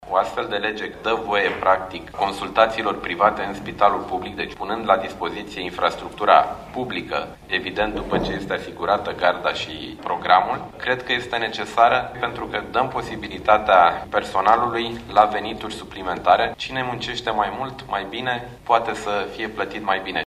Bănicioiu a subliniat, însă, că principala prioritate o constituie adoptarea legii veniturilor personalului medical: